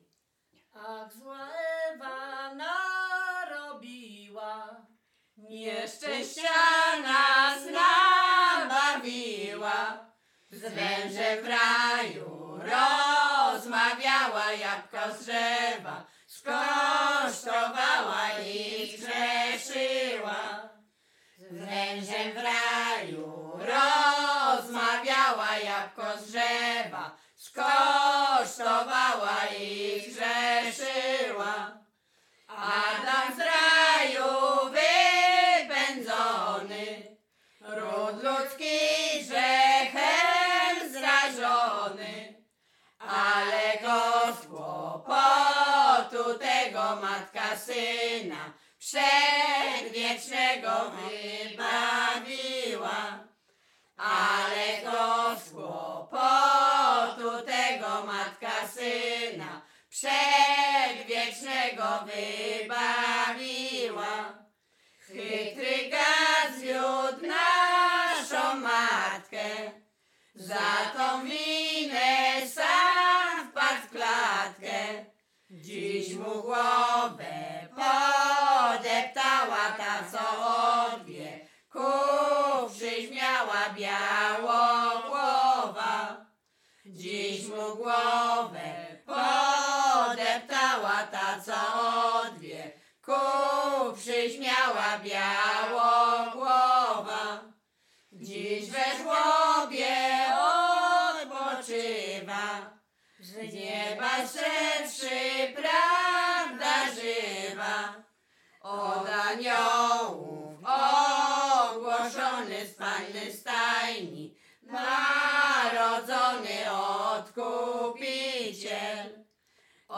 Śpiewaczki z Mroczek Małych
województwo łodzkie, powiat sieradzki, gmina Błaszki, wieś Mroczki Małe
Kolęda